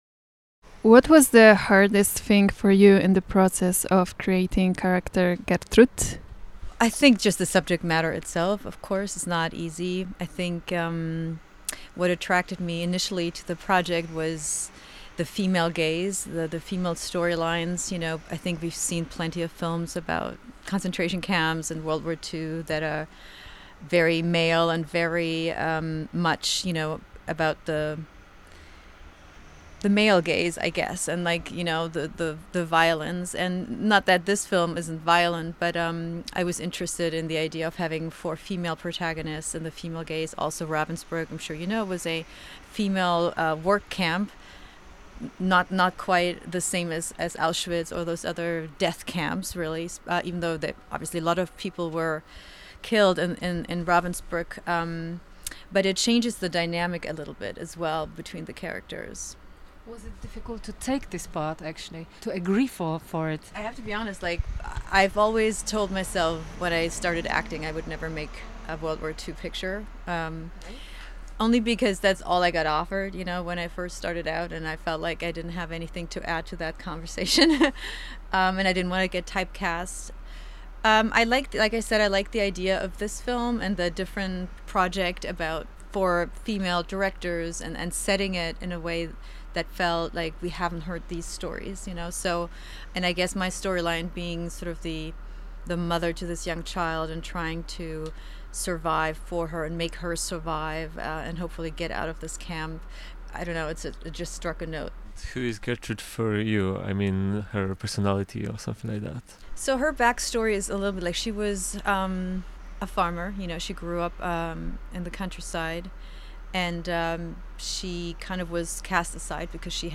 wywiad-z-diane-kruger.mp3